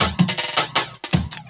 12 LOOP11 -L.wav